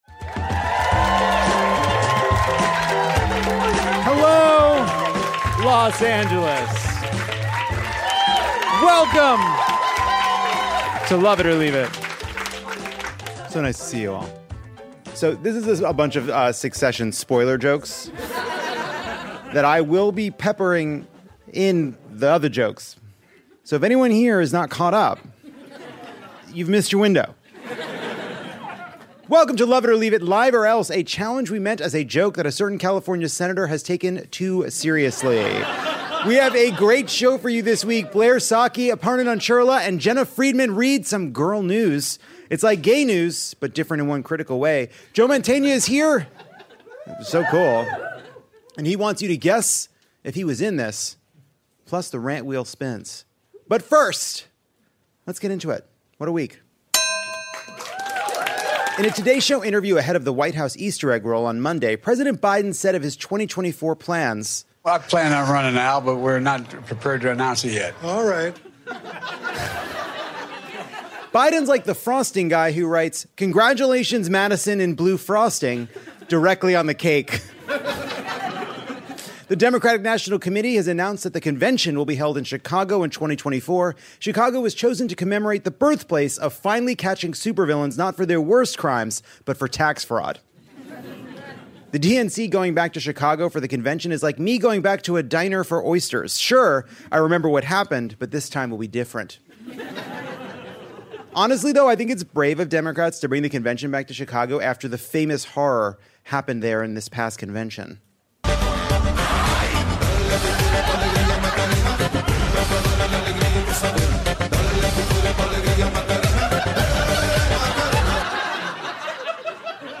Jon reaches out to the deranged Republican billionaires in the audience.